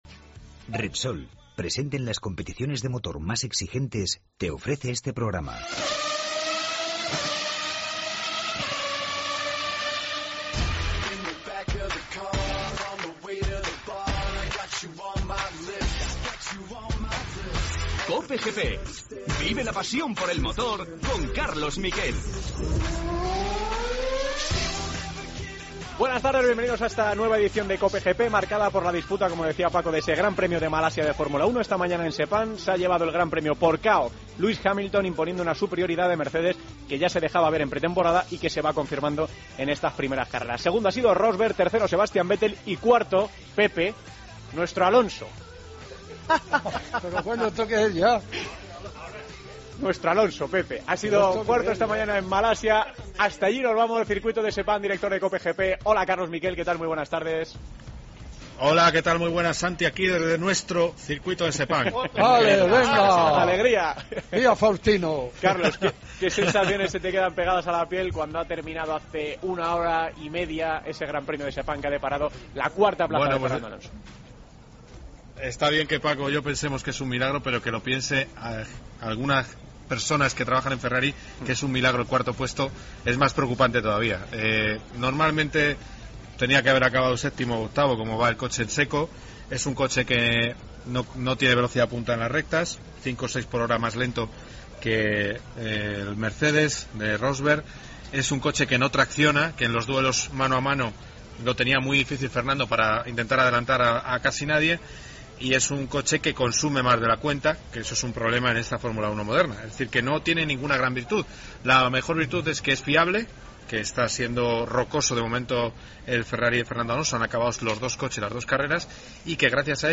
AUDIO: Analizamos el Gran Premio de Malasia de Fórmula 1. Escuchamos a Fernando Alonso.